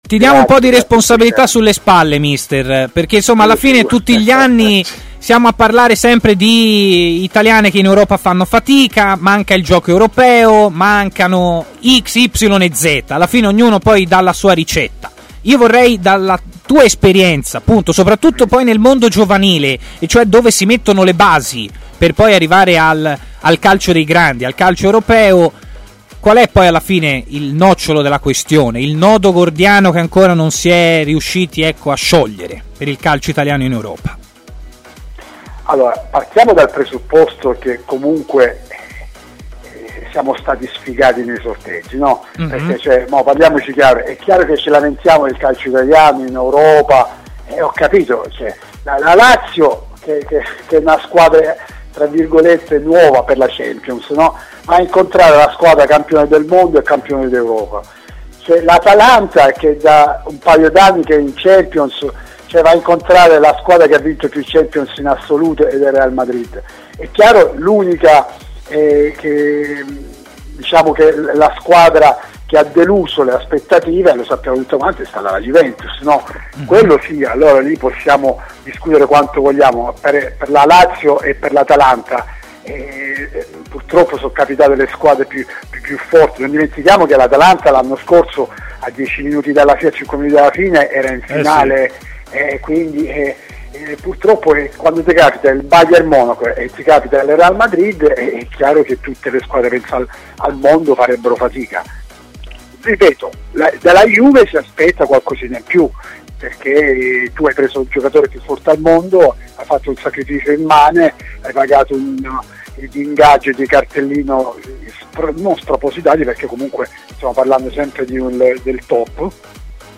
Intervenuto ai microfoni di TMW Radio, l'ex difensore Luigi Garzya ha parlato dei risultati delle tre italiane impegnate in Champions League: "Siamo stati un po' sfigati nei sorteggi, diciamocelo pure...